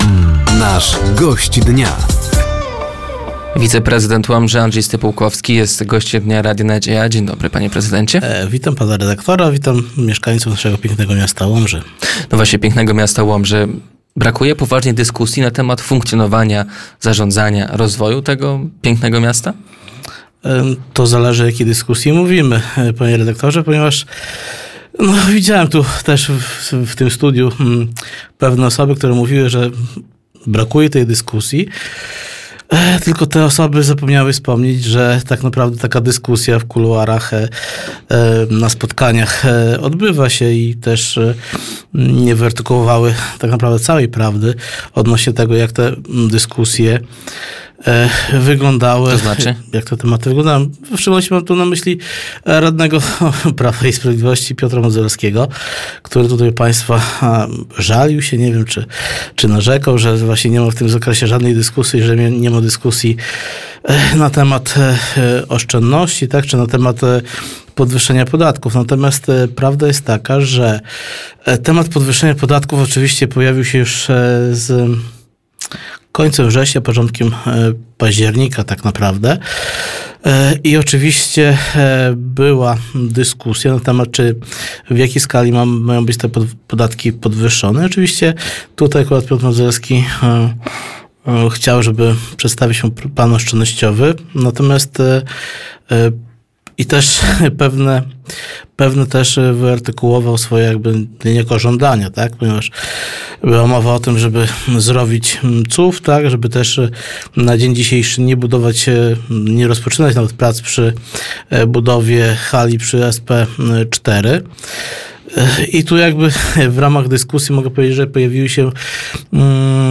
Gościem Dnia Radia Nadzieja był wiceprezydent Łomży Andrzej Stypułkowski. Tematem rozmowy była polityka miasta, podatki, budżet na przyszły rok, sytuacja samorządów i oświata.